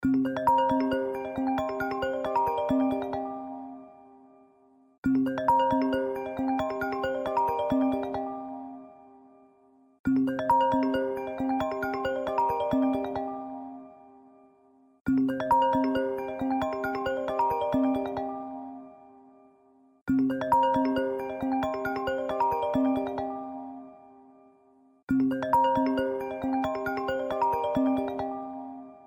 Без слов
Мелодичные